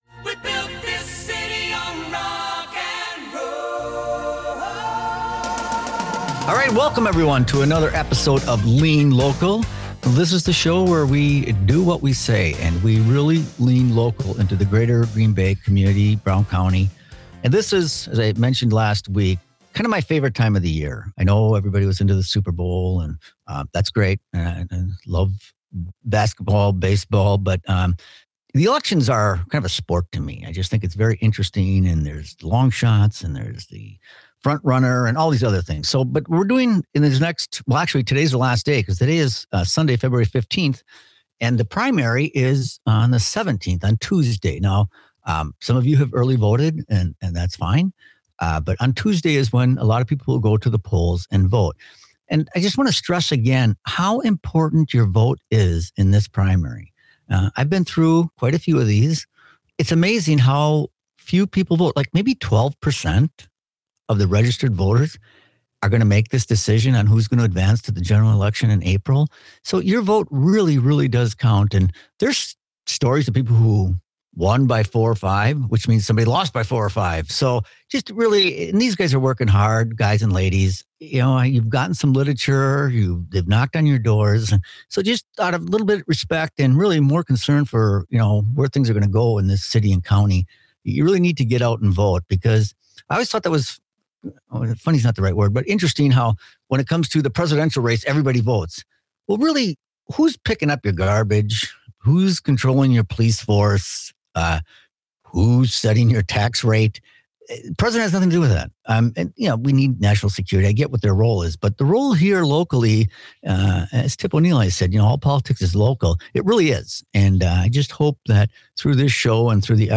Dive into the heart of community issues with 'Lean Local,' hosted by former Green Bay Mayor Jim Schmitt. This refreshing political and issues program bypasses traditional left-versus-right rhetoric. Instead, it 'leans local' with insightful discussions and grassroots solutions, focusing on what truly matters in our neighborhoods and communities.